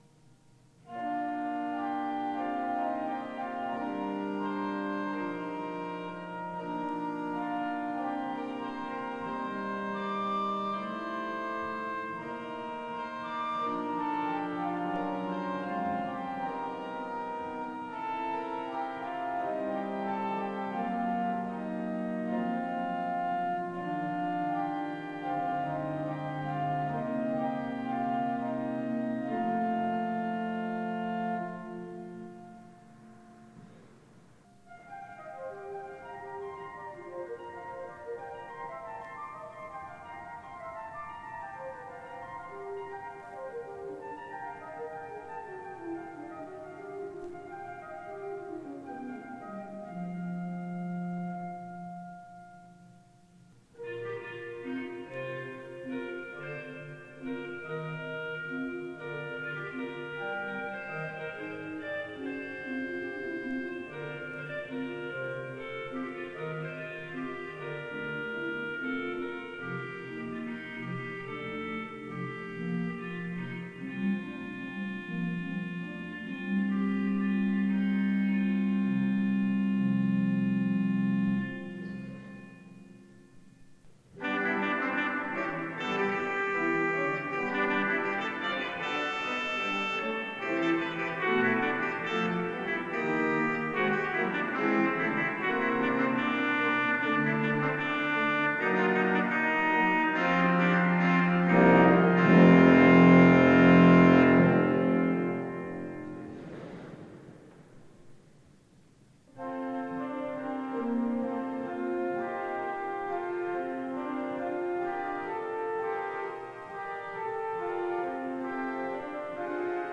6 Versetten
DieVersetten über dem Pfingstchoral sind Miniaturen in denen der heilige Geist vom leisen Säuseln und Vogelgezwitscher bis zum aufbrausenden Wind hörbar wird.
Die 1904 von Walcker, Ludwigsburg gebaute Orgel der Georgskirche ist in großen Teilen noch im Original erhalten.